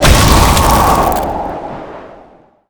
iceblast_explode.wav